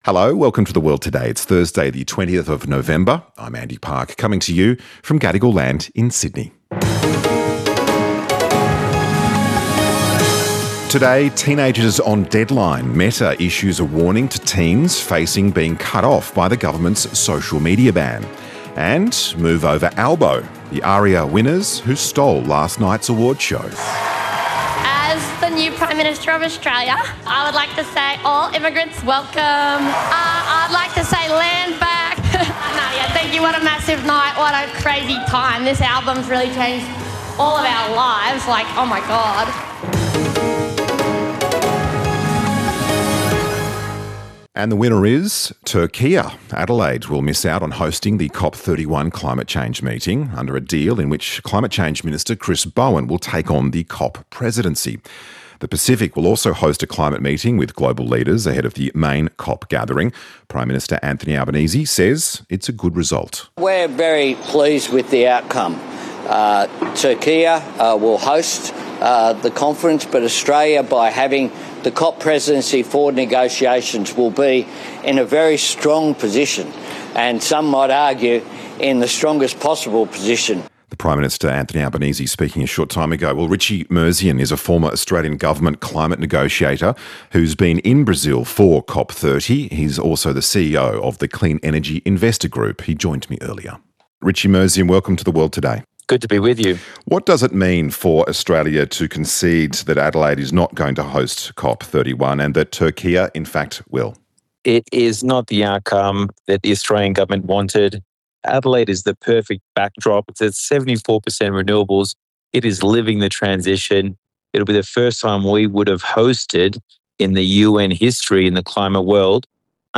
The World Today is a comprehensive current affairs program which backgrounds, analyses, interprets and encourages debate on events and issues of interest and importance to all Australians.